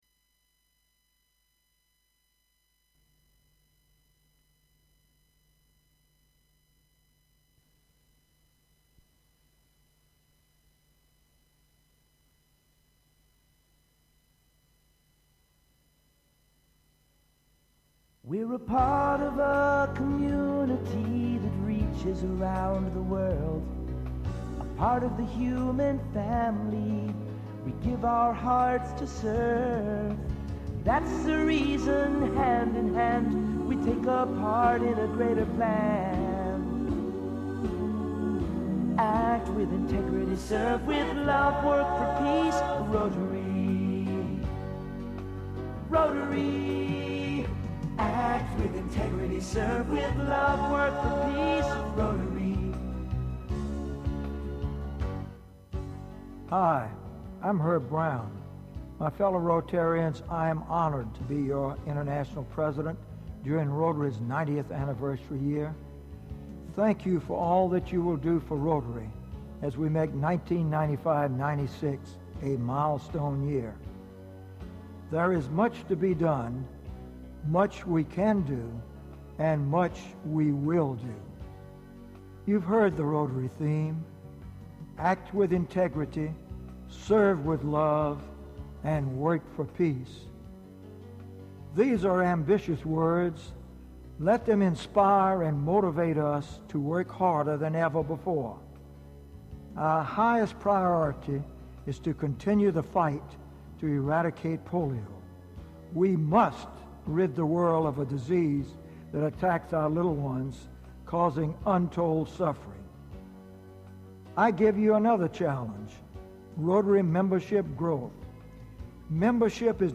RIP Herbert G Brown speaking about the theme for 1995-96, Act With Integrity, Serve With Love, Work For Peace.